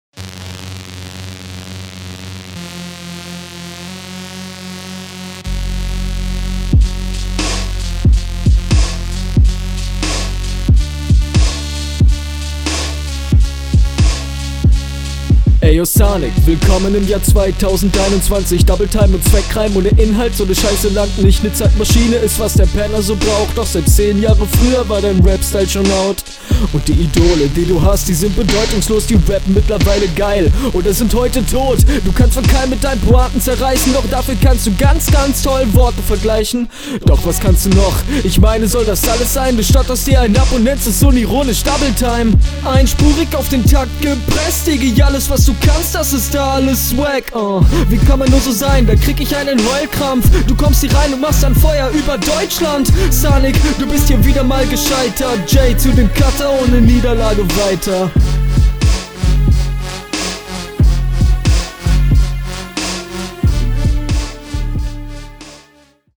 Find der Style kommt auf so einen Beat anders dope.